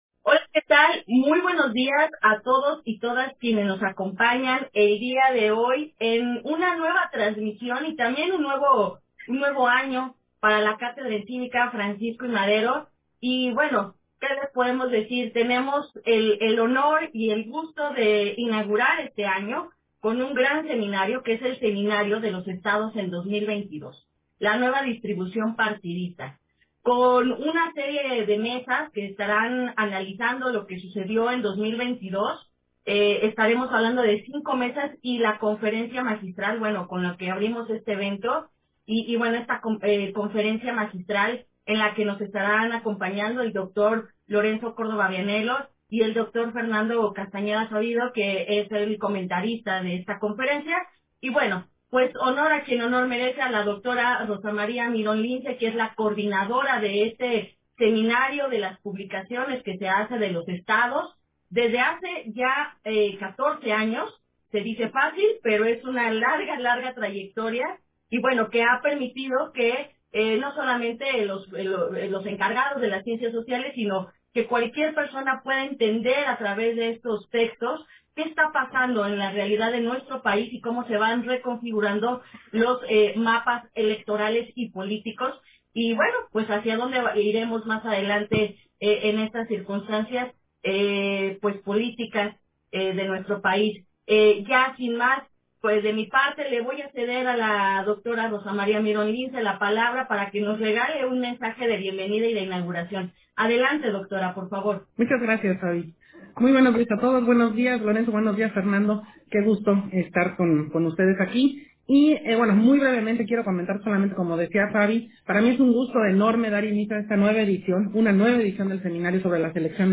Conferencia magistral de Lorenzo Córdova en el seminario, Los Estados en 2022, la nueva distribución partidista